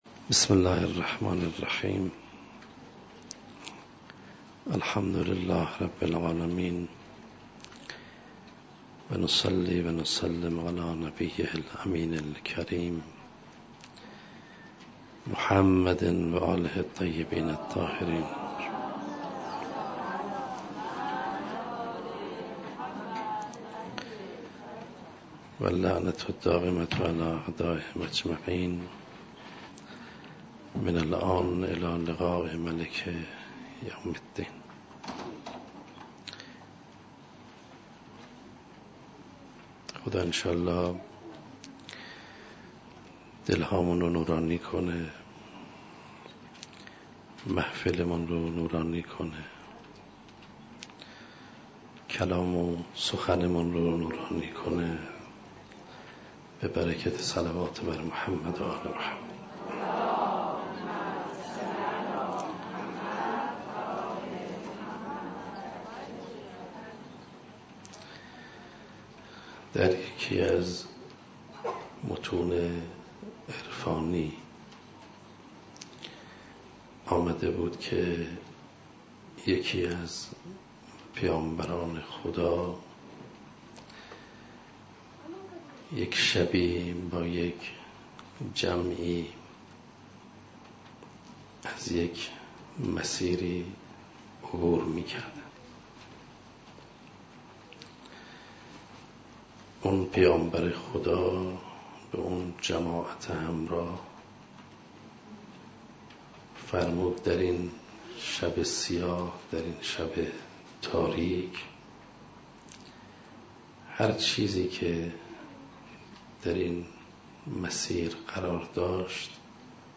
126 - تلاوت قرآن کریم